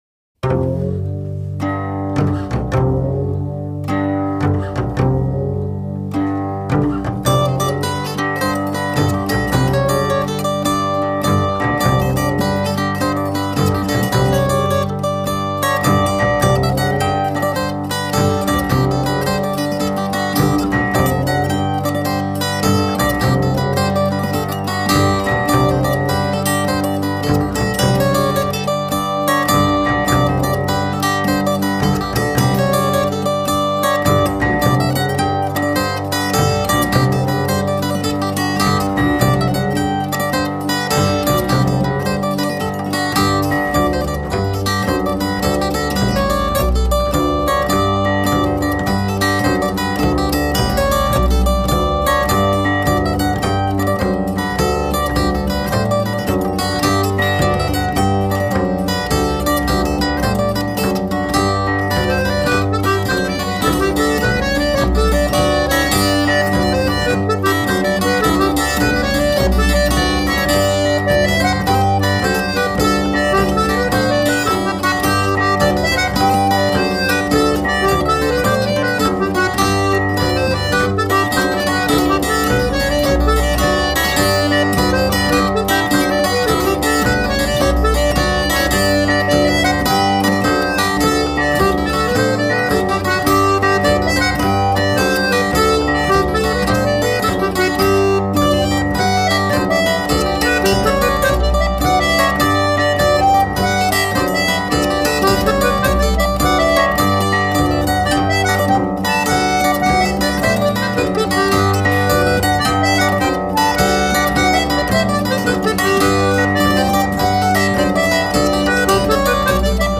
Musiques d'Alsace et d'Ailleurs
contredanse ou ...